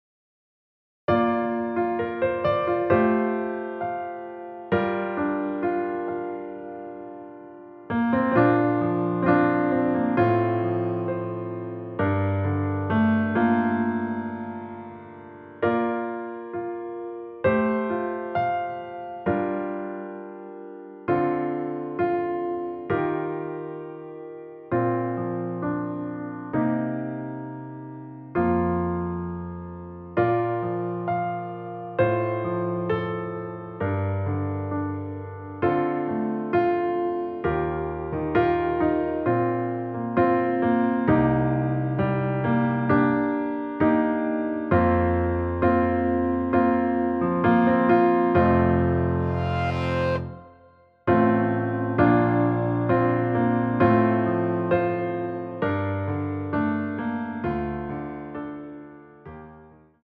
Bb
◈ 곡명 옆 (-1)은 반음 내림, (+1)은 반음 올림 입니다.
앞부분30초, 뒷부분30초씩 편집해서 올려 드리고 있습니다.